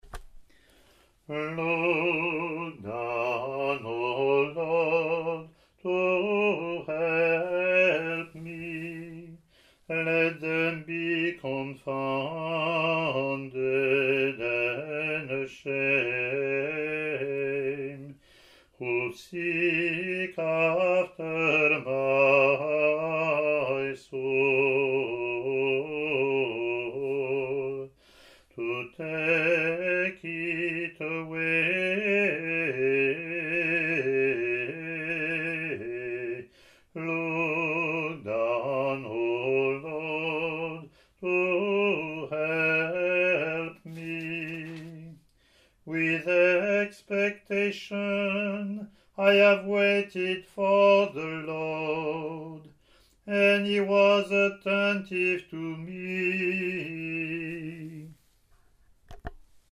English antiphon – English verseLatin antiphon)